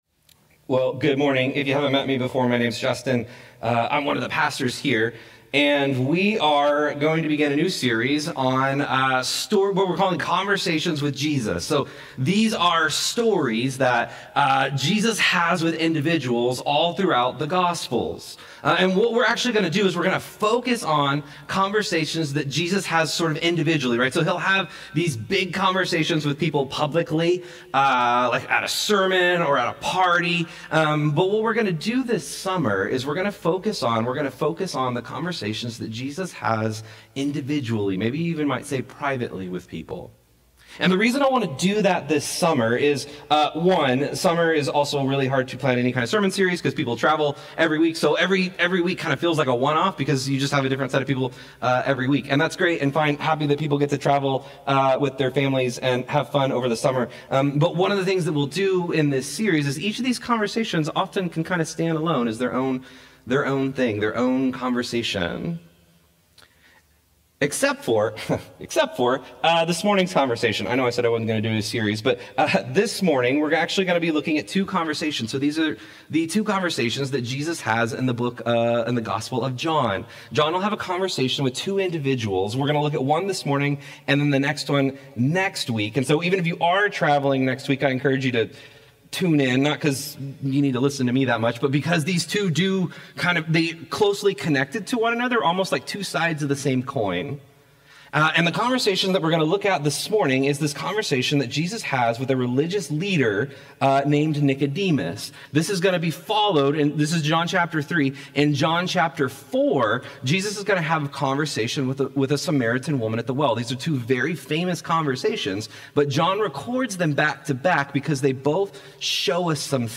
This sermon explores how Jesus lovingly tears down our defenses and invites us into the vulnerable, powerful life of the Kingdom.